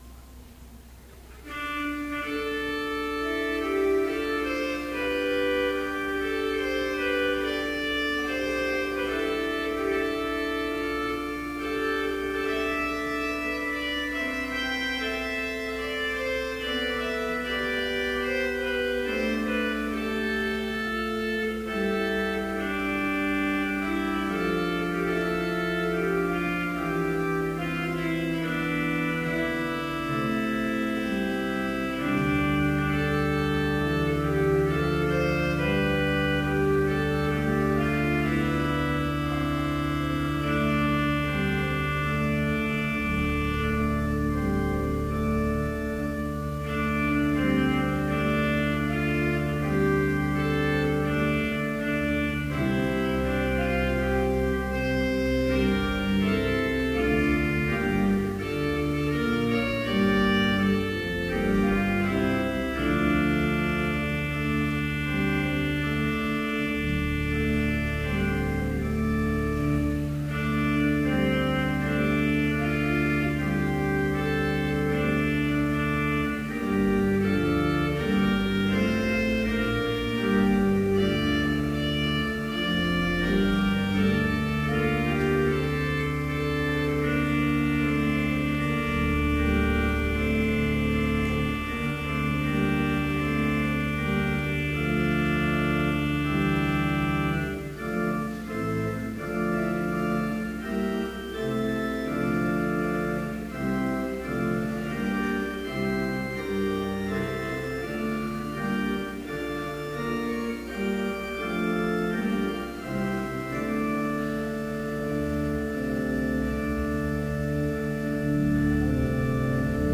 Complete service audio for Chapel - February 24, 2015